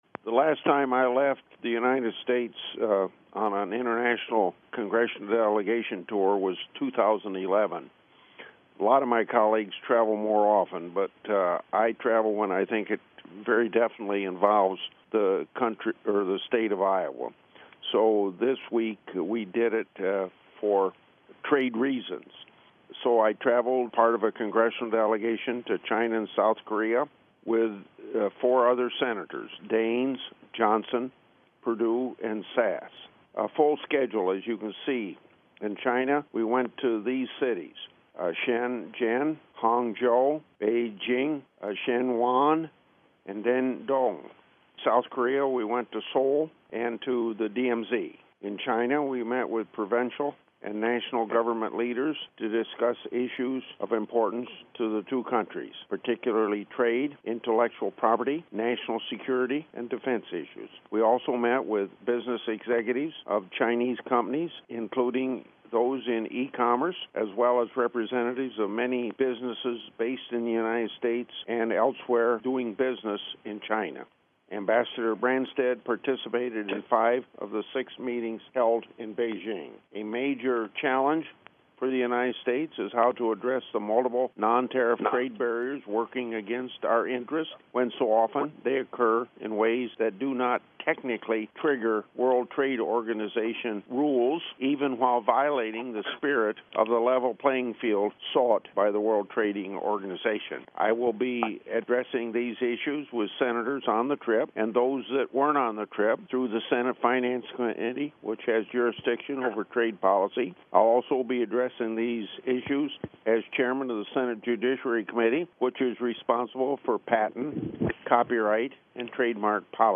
Conference Call on Asia Co-Del Part 1